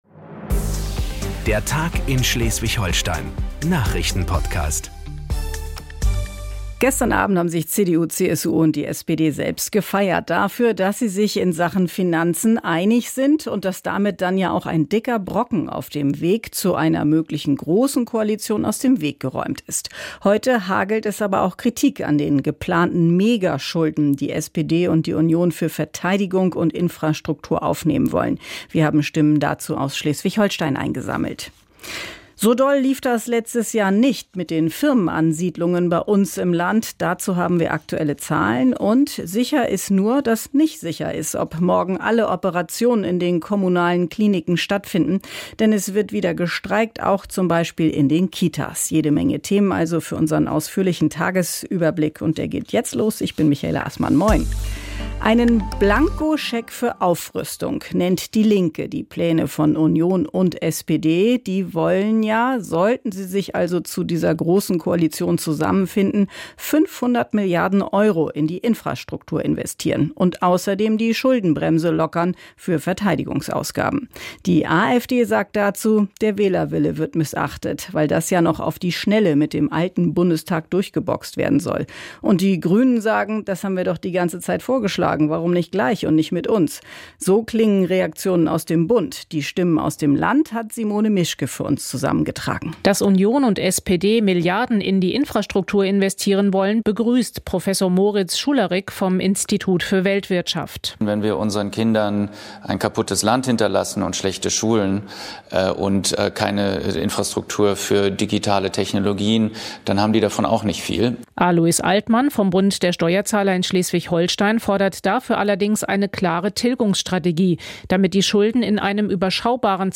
1 Der Tag in SH vom 05.03.2025 8:24 Play Pause 6h ago 8:24 Play Pause Reproducir más Tarde Reproducir más Tarde Listas Me gusta Me gusta 8:24 Der Tag in Schleswig-Holstein - alles was wichtig ist für Schleswig-Holstein hören Sie im Nachrichtenpodcast von NDR Schleswig-Holstein. Wir fassen den Tag zusammen, ordnen ein und beleuchten Hintergründe.